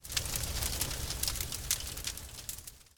sparkles.mp3